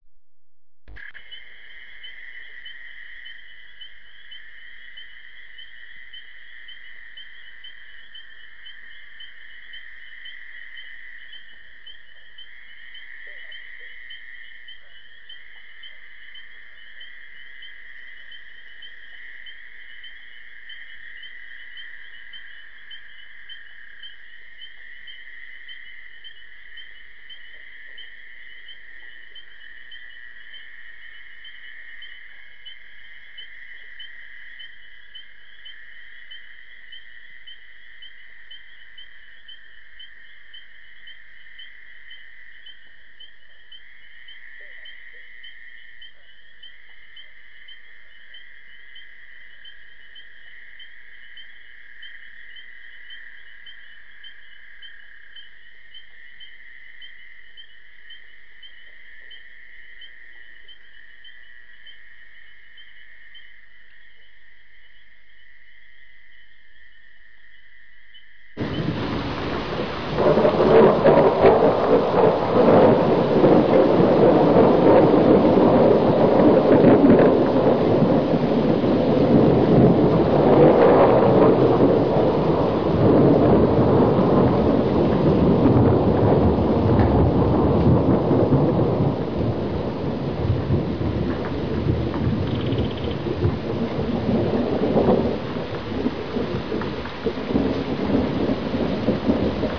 Фонограмма к игре  "Гроза в лесу"     Песня "Дождя не боимся" слова,      мр3